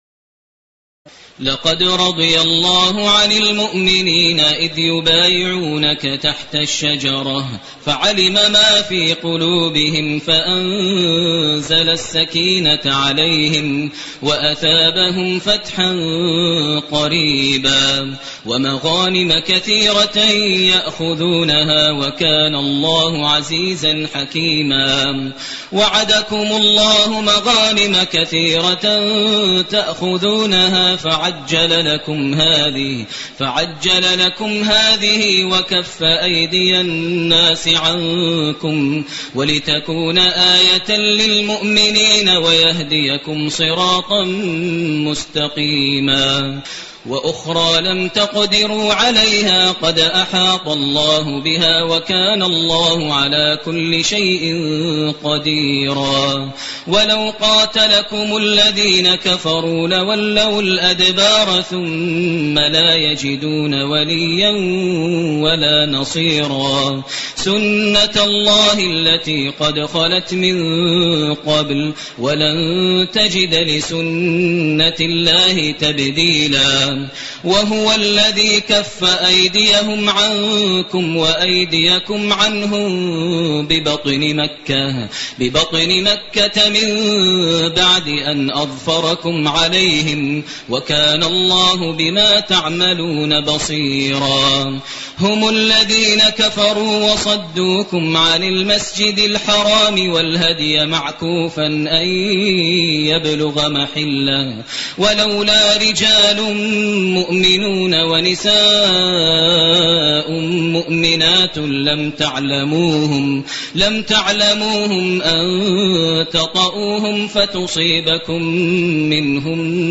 تراويح ليلة 25 رمضان 1429هـ من سور الفتح (18-29) الحجرات و ق و الذاريات Taraweeh 25 st night Ramadan 1429H from Surah Al-Fath and Al-Hujuraat and Qaaf and Adh-Dhaariyat > تراويح الحرم المكي عام 1429 🕋 > التراويح - تلاوات الحرمين